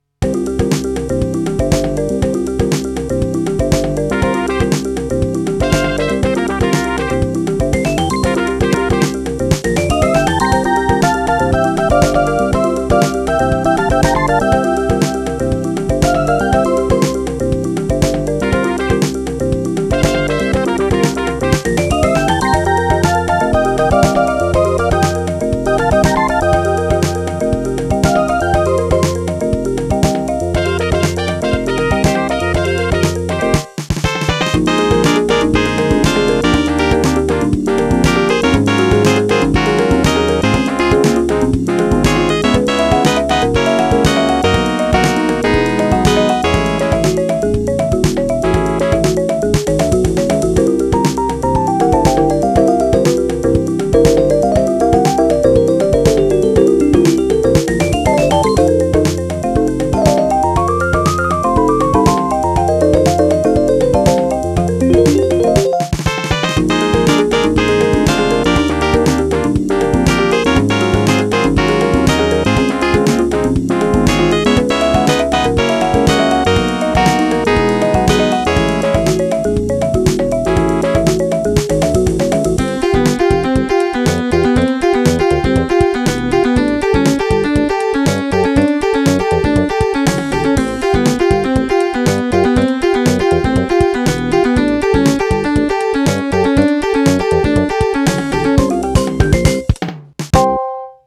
Accès à un fichier Yamaha PSS-380 Demo.flac